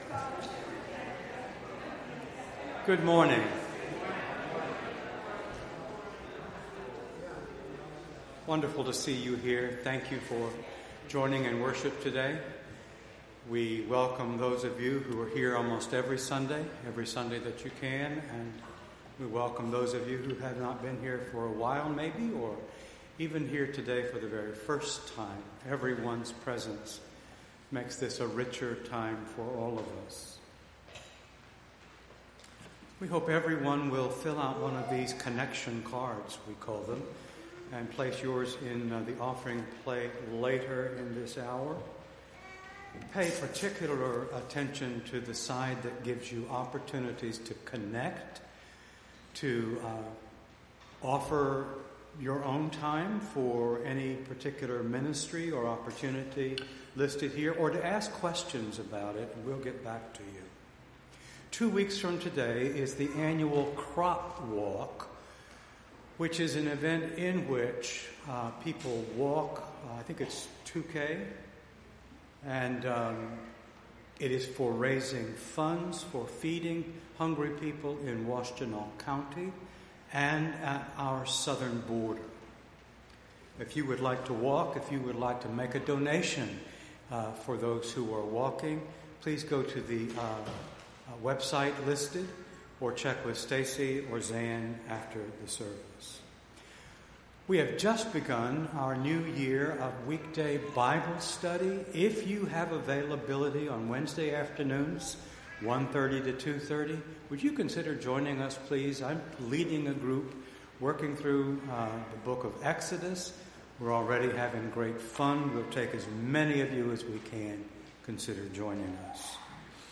Entire September 22nd Service